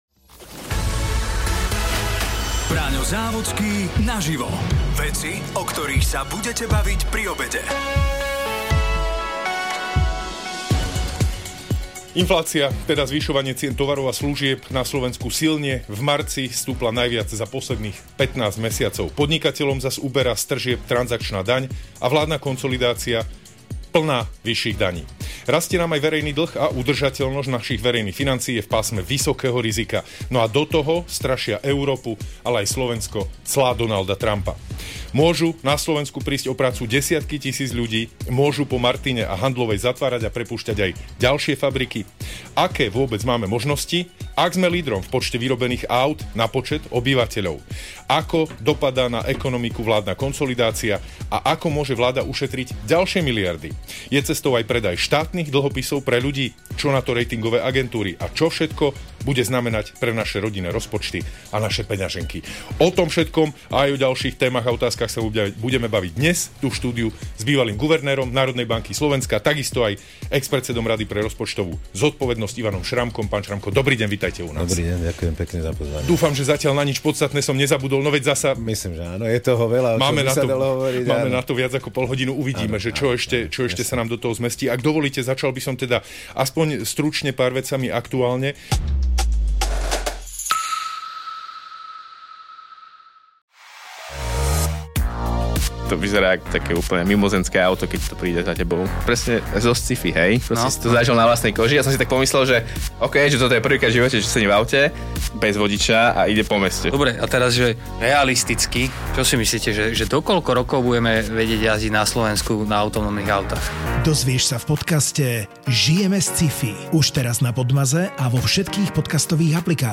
sa rozprával s bývalým guvernérom Národnej banky Slovenska aj expredsedom Rady pre rozpočtovú zodpovednosť